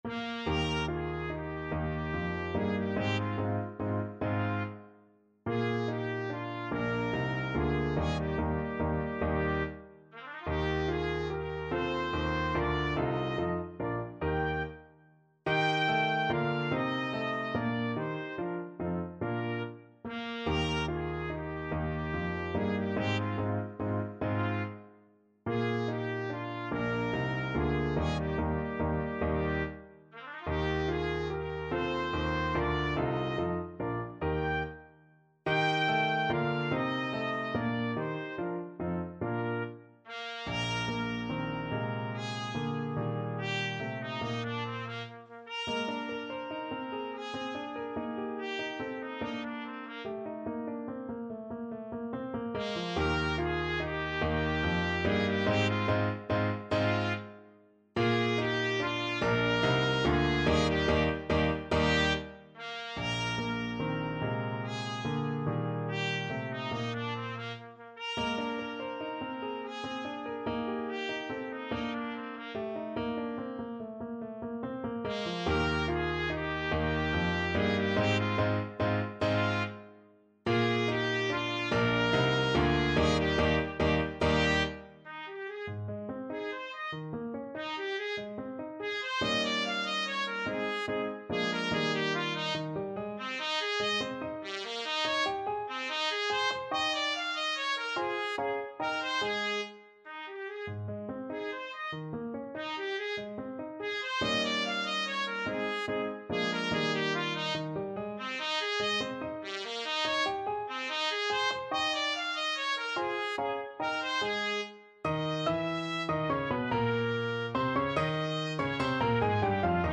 Classical Beethoven, Ludwig van Minuet, WoO 82 Trumpet version
Trumpet
3/4 (View more 3/4 Music)
Moderato =c.144
Classical (View more Classical Trumpet Music)